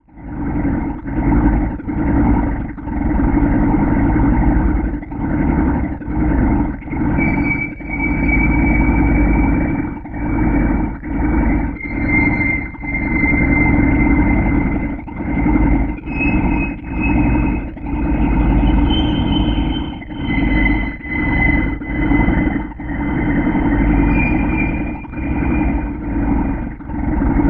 91.1WAILS.wav